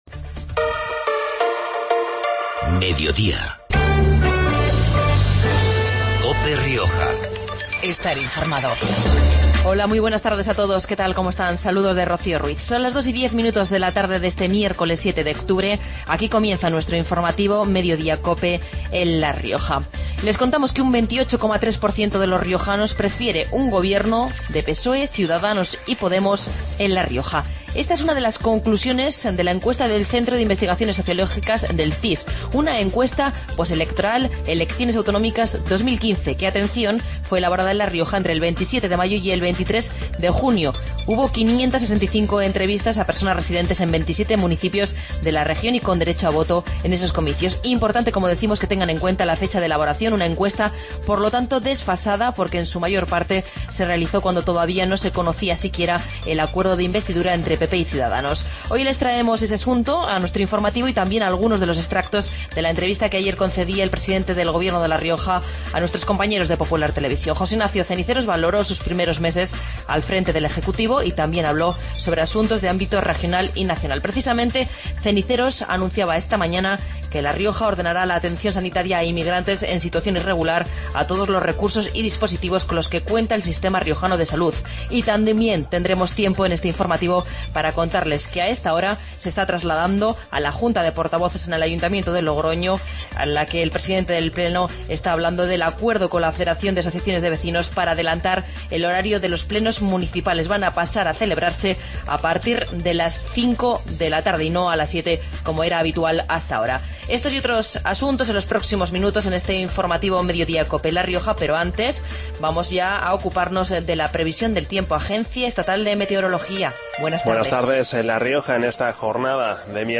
Informativo Mediodia en La Rioja 07-10-15